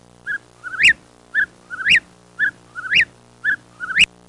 Death Of A Whipperwill Sound Effect
Download a high-quality death of a whipperwill sound effect.